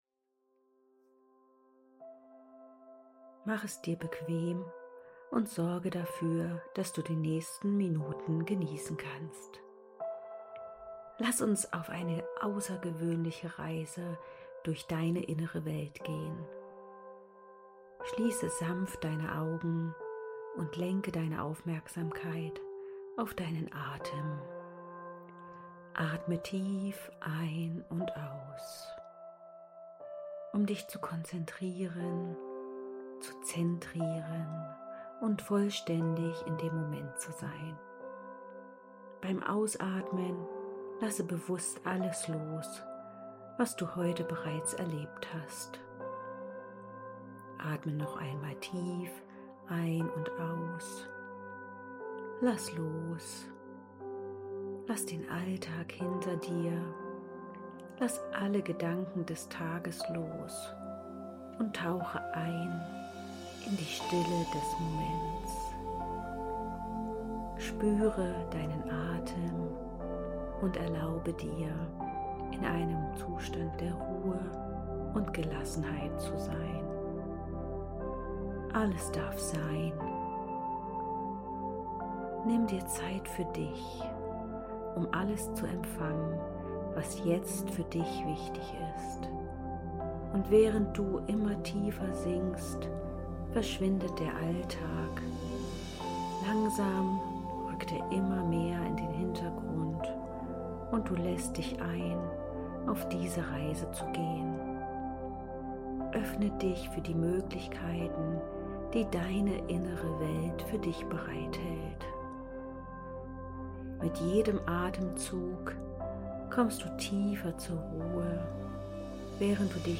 Modul-2_Meditation-Heldinnenreise.mp3